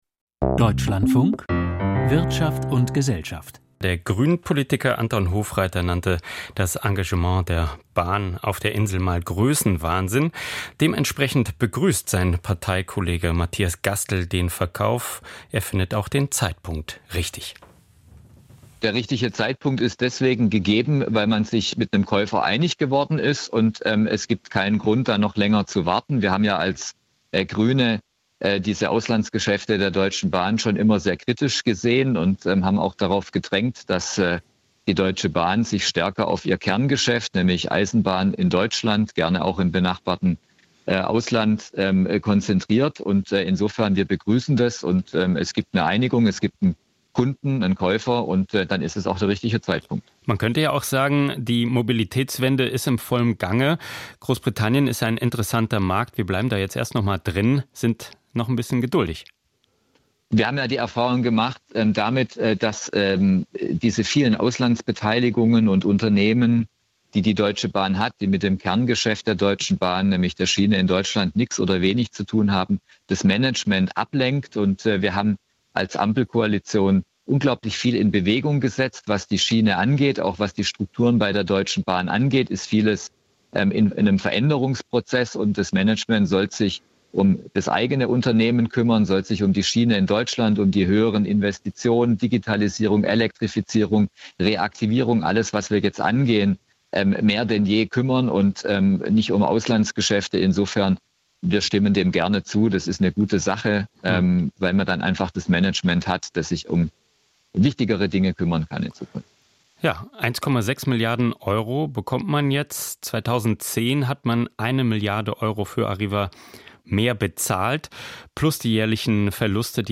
Widersprüchliche Bahngeschäfte - Interview Matthias Gastel (Grüne)